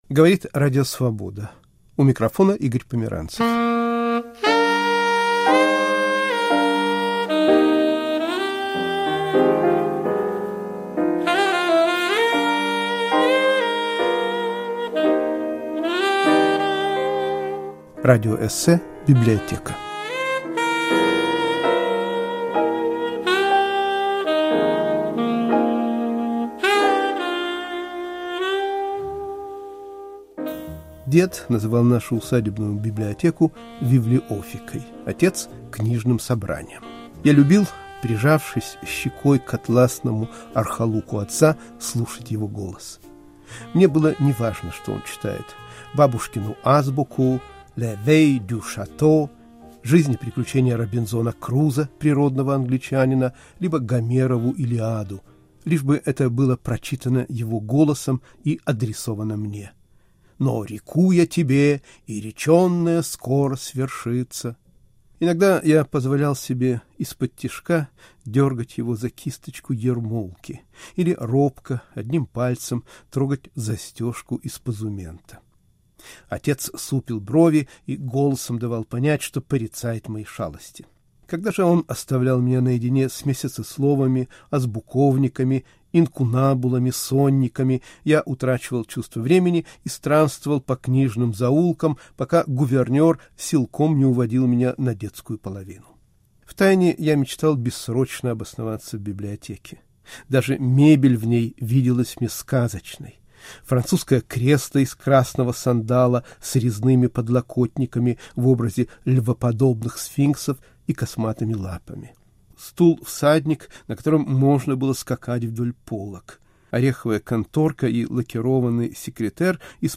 Радиоэссе "Библиотека"